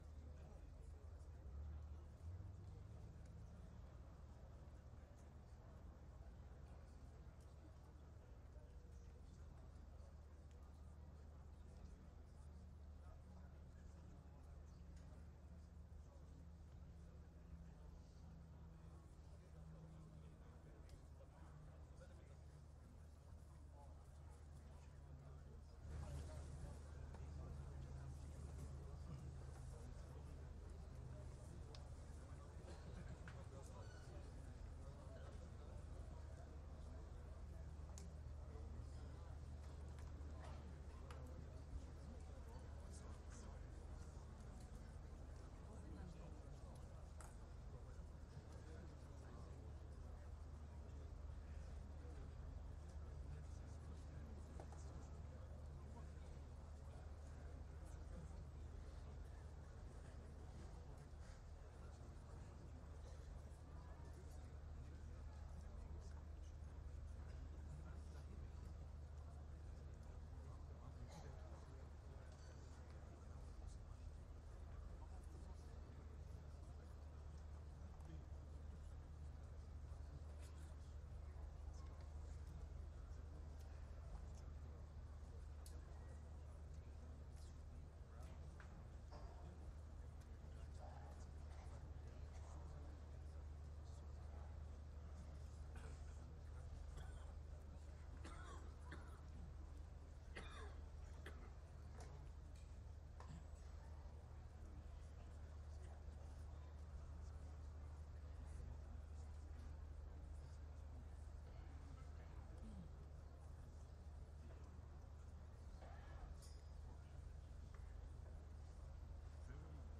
Tamil translation of Friday Sermon delivered by Khalifa-tul-Masih on July 26th, 2024 (audio)